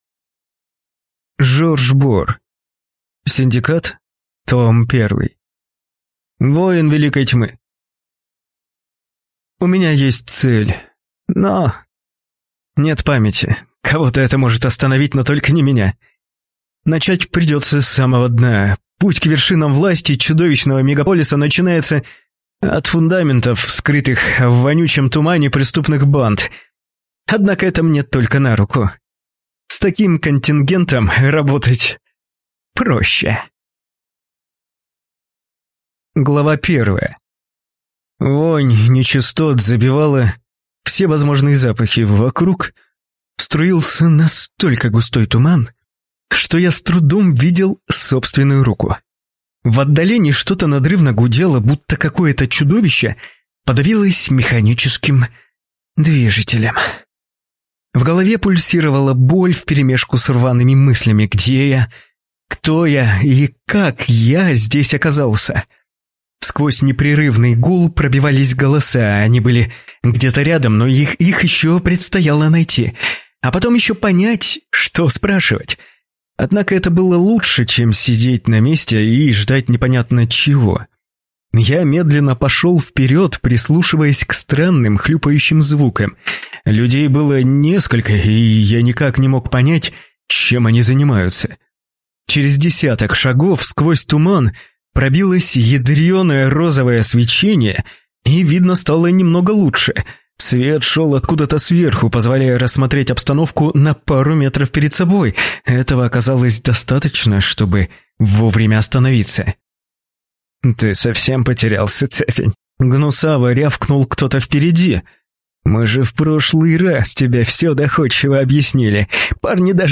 Аудиокнига Синдикат. Том 1 | Библиотека аудиокниг
Прослушать и бесплатно скачать фрагмент аудиокниги